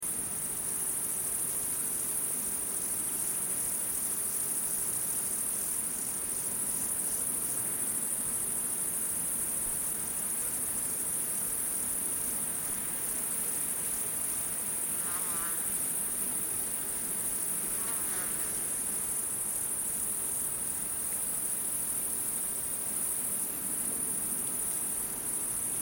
دانلود صدای حشره 7 از ساعد نیوز با لینک مستقیم و کیفیت بالا
جلوه های صوتی